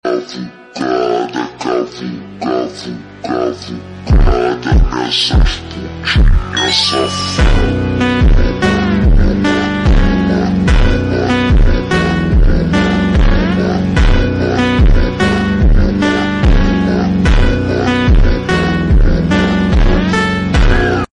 Super Slowed